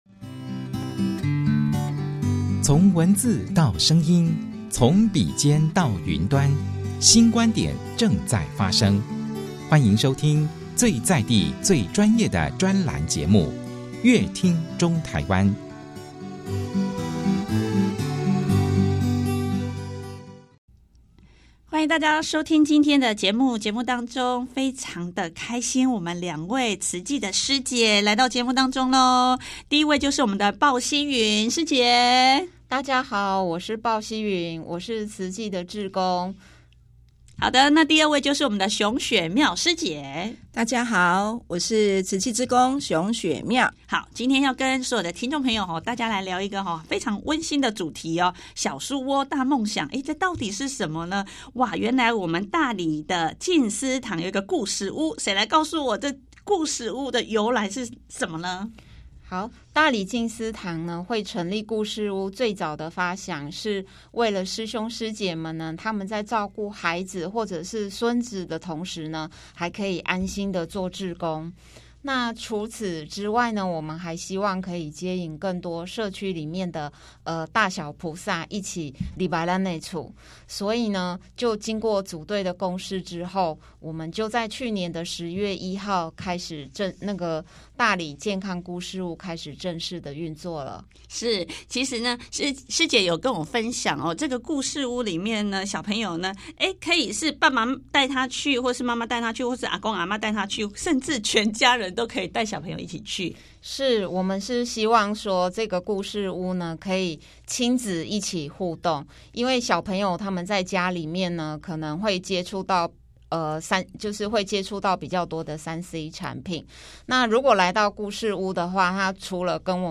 期盼透過健康故事屋讓大家從故事中得到實用的生活知識，除了聽故事之外，也能將身心靈安頓好，將來遇到挫折時，更有能力與勇氣用正向能量面對問題，這正是慈濟創建健康故事屋的初衷與期盼，歡迎鎖定本集節目收聽相關精彩的專訪內容。